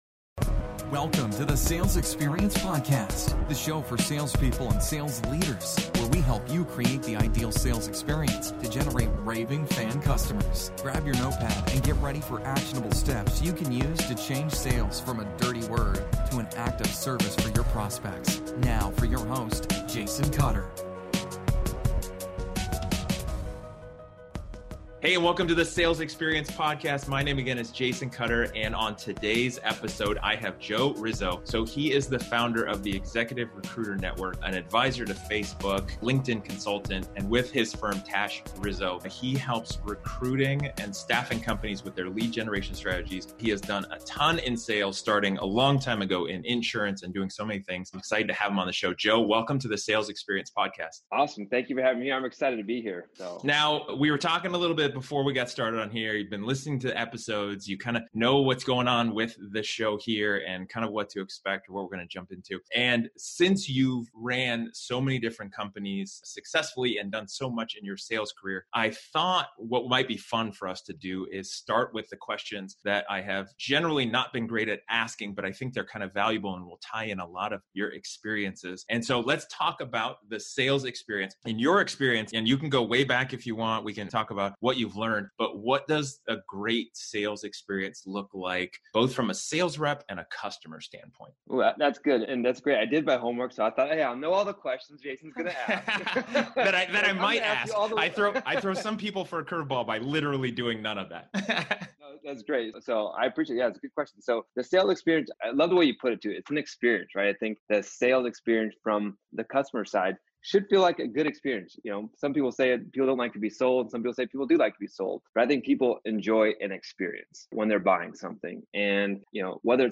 I must say that this was a great conversation between two people on the same wavelength of how sales should be done by professionals.